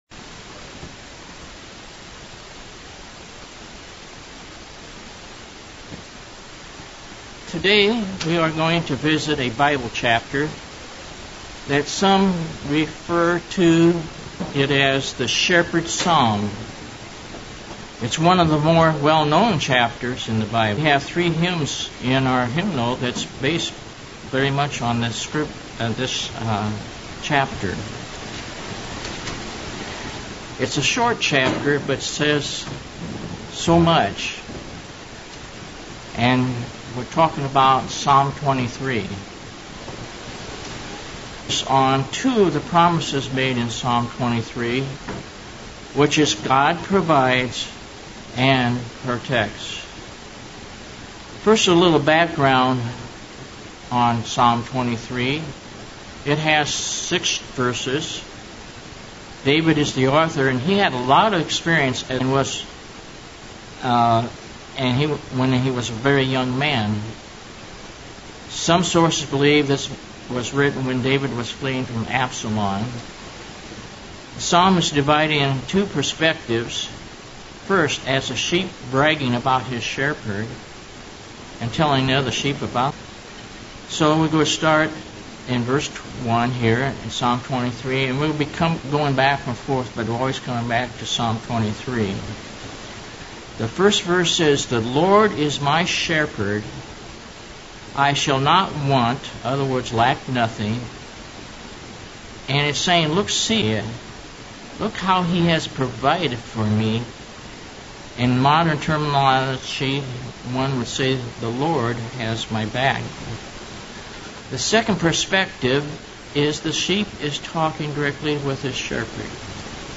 Sermonette looking at the subject of a shepherd and his characteristics, specifically as applies to Ps. 23. God cares for our physical and spiritual needs and his sheep hear his voice.